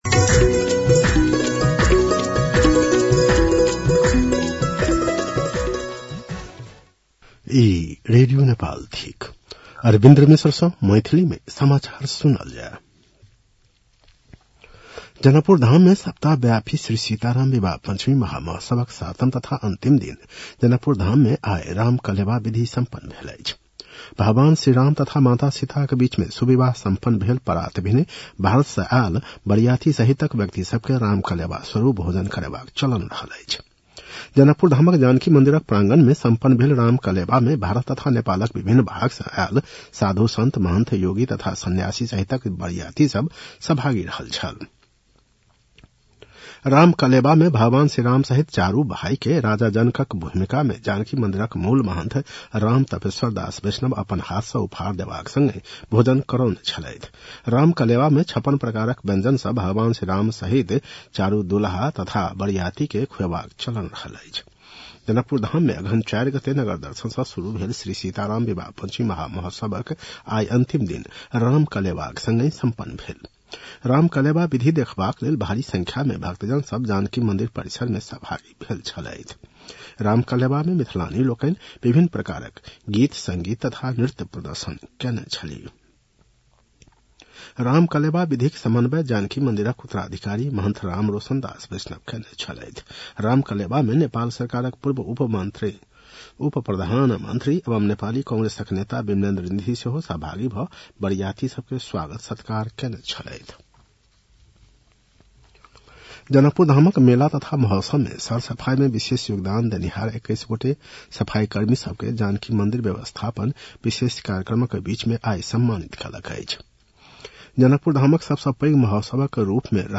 मैथिली भाषामा समाचार : १० मंसिर , २०८२